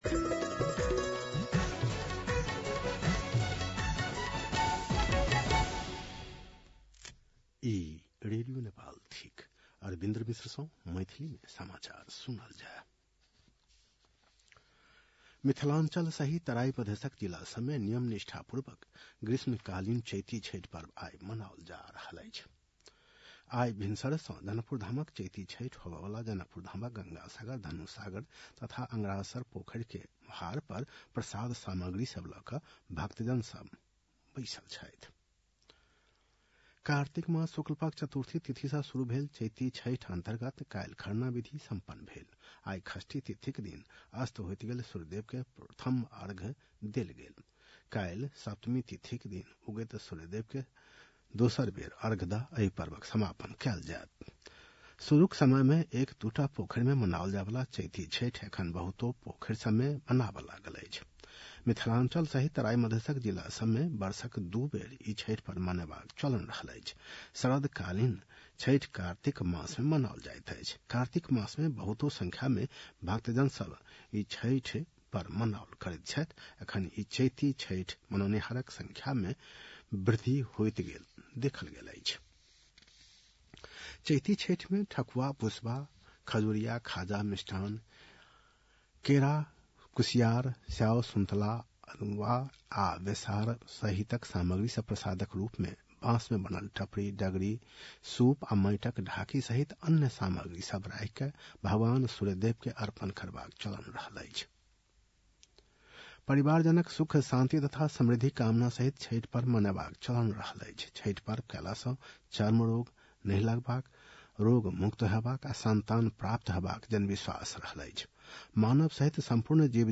मैथिली भाषामा समाचार : २१ चैत , २०८१